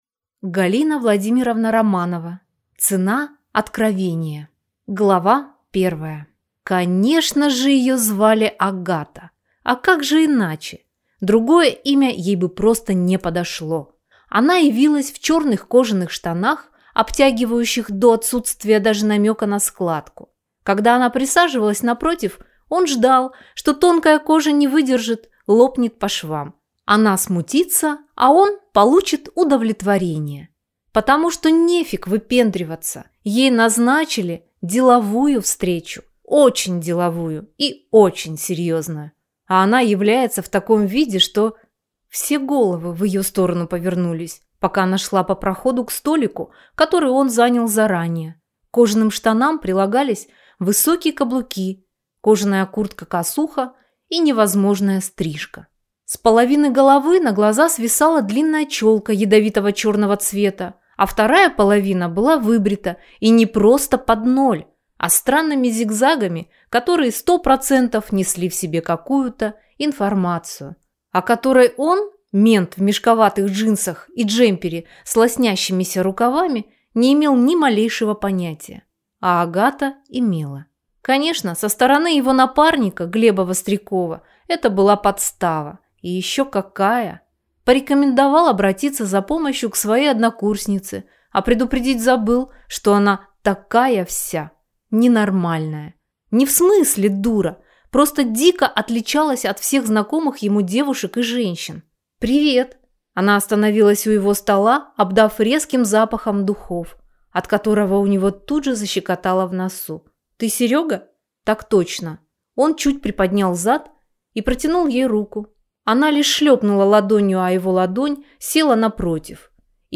Аудиокнига Цена откровения | Библиотека аудиокниг